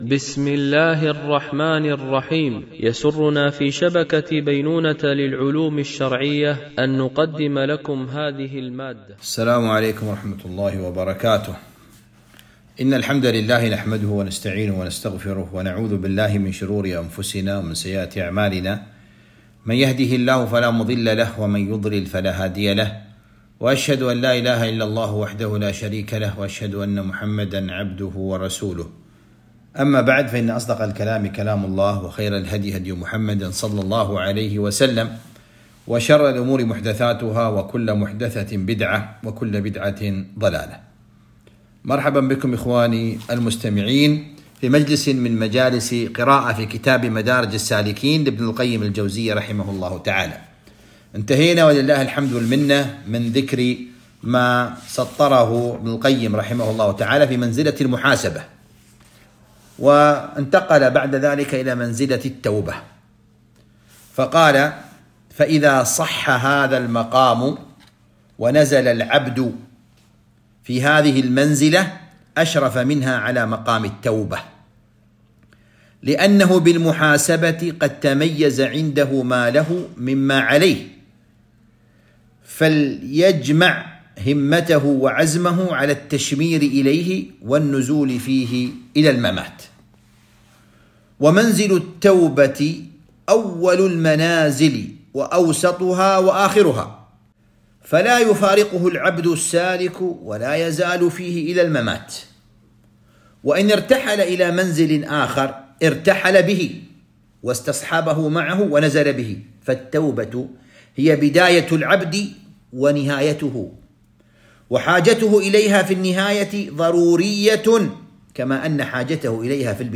قراءة من كتاب مدارج السالكين - الدرس 23